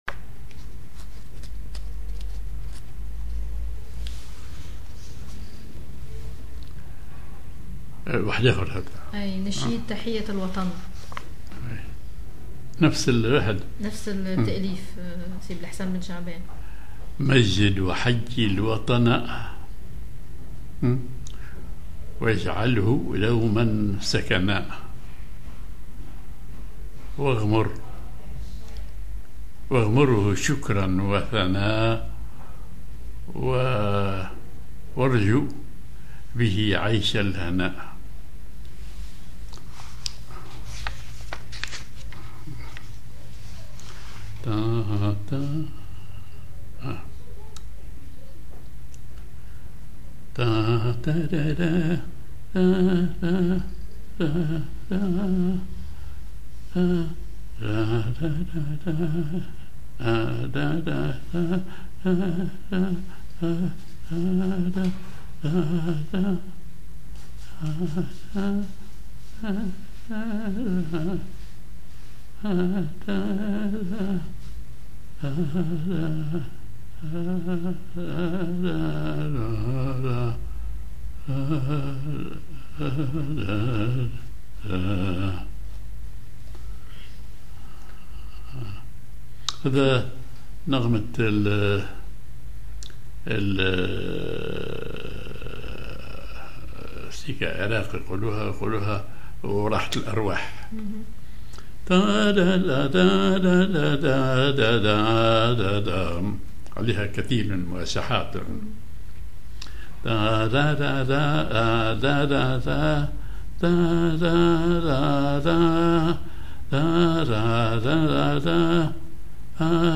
Maqam ar راحة الارواح
genre نشيد